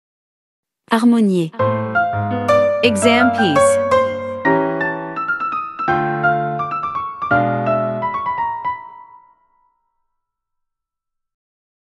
Sample MP3 | Bars: 75b-79
Professional-level Piano Exam Practice Materials.
• Vocal metronome and beats counting